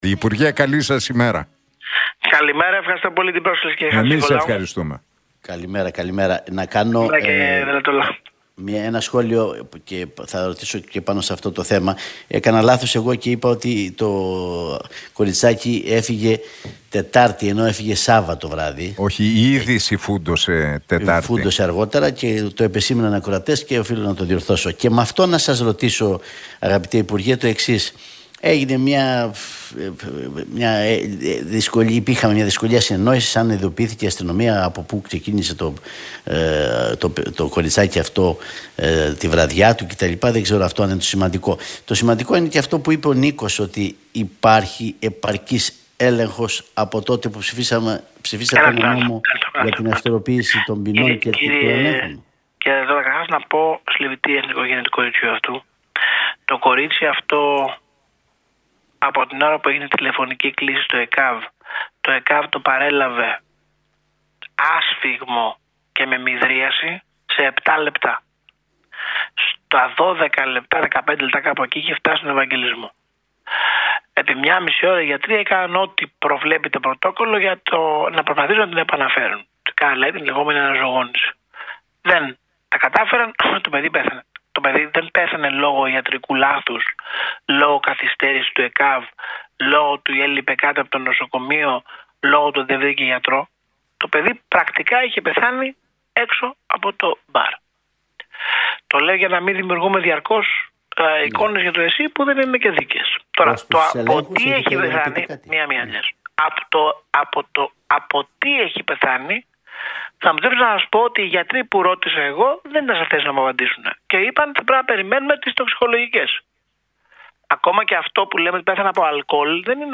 Γεωργιάδης στον Realfm 97,8: Δεν καταλαβαίνω γιατί θεωρείται «καρφί» προς τον Δένδια - Εγώ το θεωρώ υποστήριξη σε εκείνον